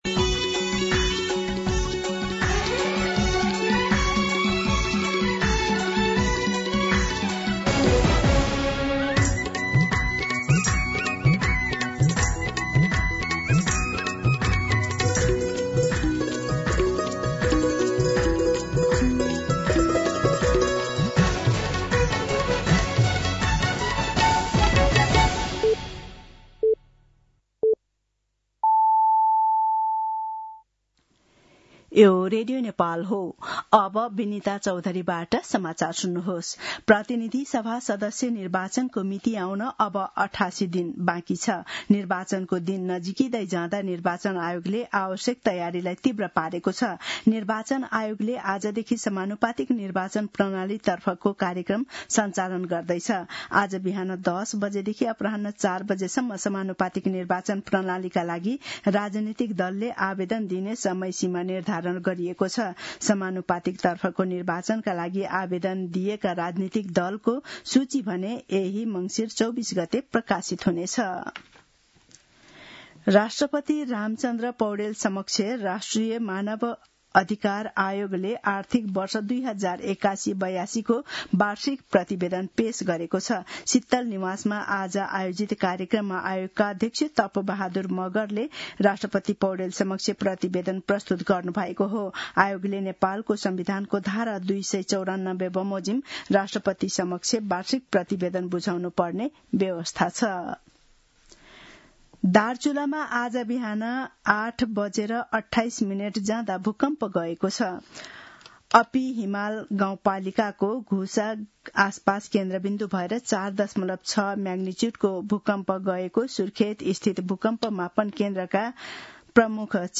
दिउँसो १ बजेको नेपाली समाचार : २१ मंसिर , २०८२